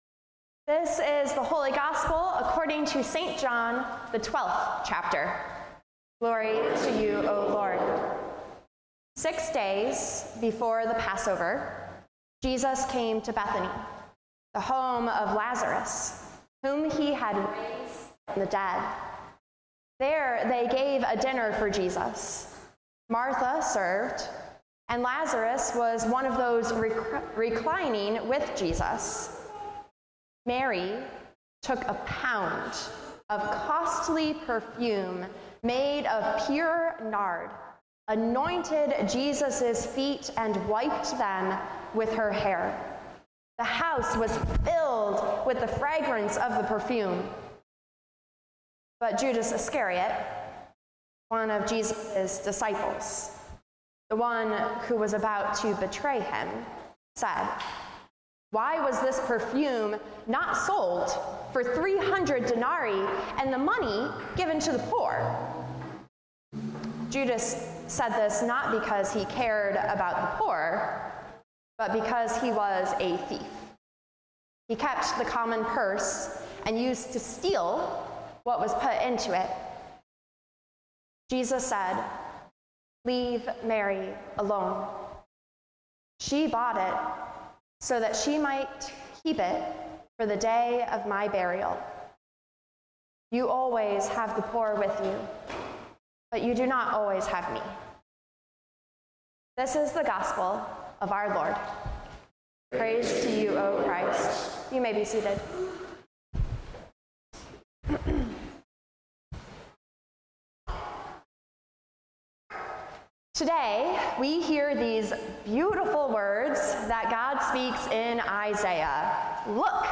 A message based on John 12:1-8: